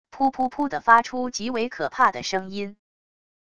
噗噗噗的发出极为可怕的声音wav音频